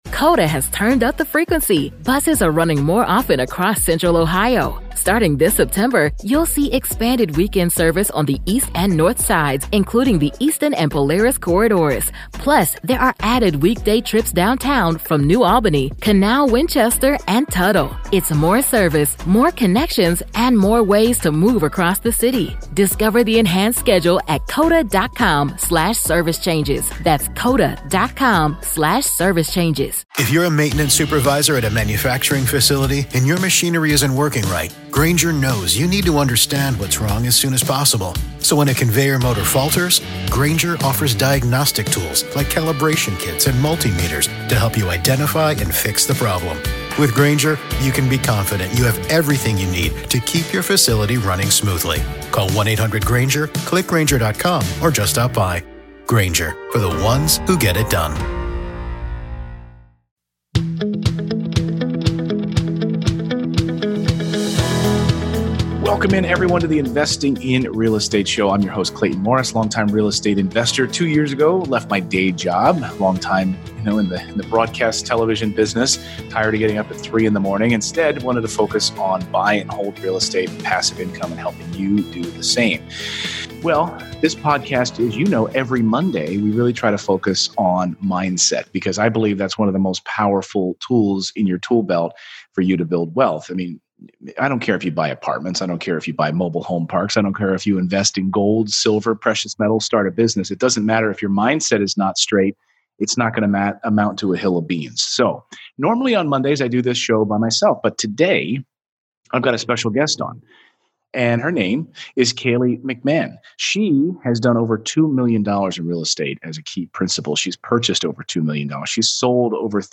Today's guest is here to share how transforming her mindset totally changed her real estate business.